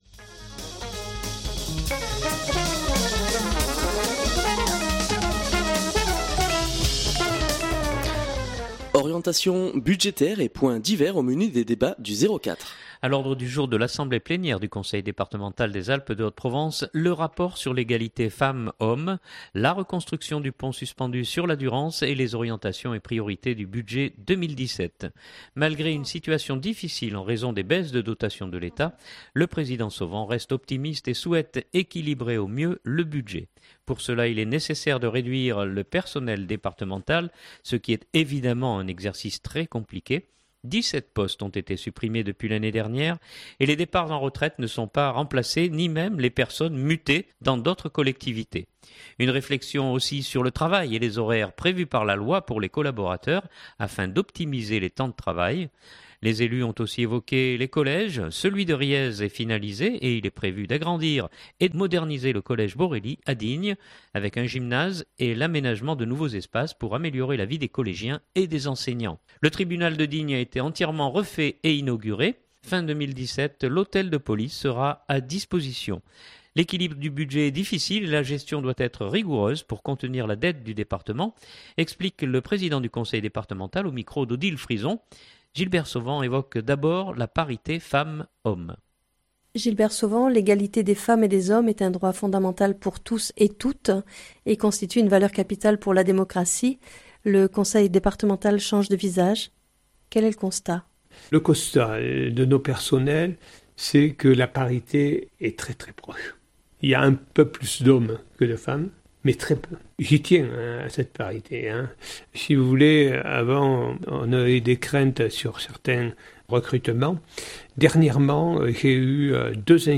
L’équilibre du budget est difficile et la gestion doit être rigoureuse pour contenir la dette du département, explique le Président du Conseil départemental